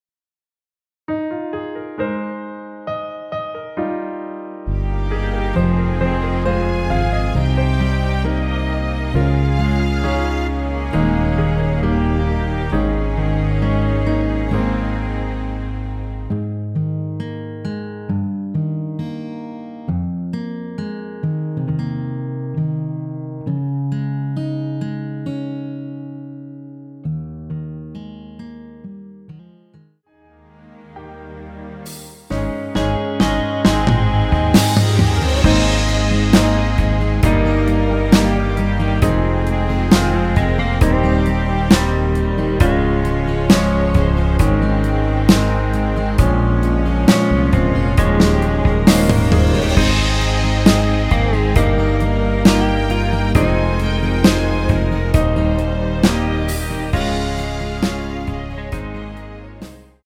원키에서(-3)내린 MR입니다.
Ab
앞부분30초, 뒷부분30초씩 편집해서 올려 드리고 있습니다.
중간에 음이 끈어지고 다시 나오는 이유는